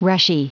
Prononciation du mot rushy en anglais (fichier audio)
Prononciation du mot : rushy